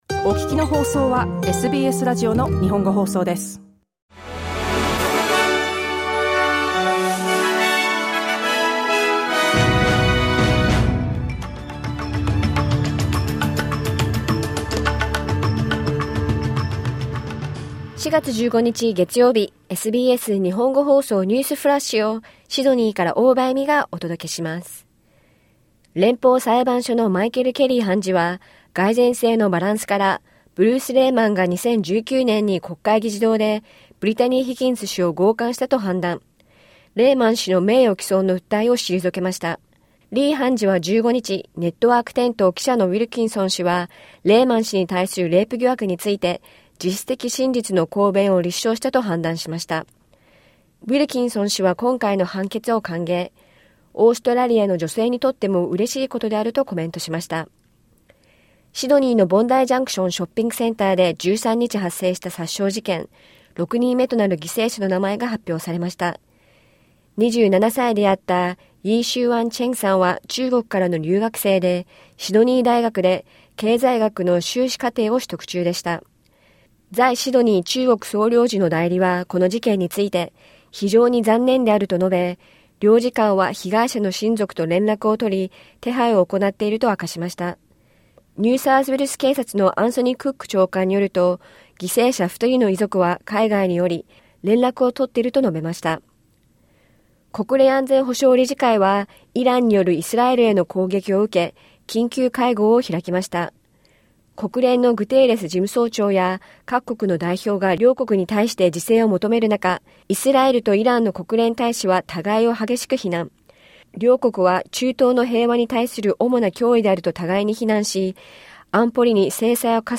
SBS日本語放送ニュースフラッシュ 4月15日月曜日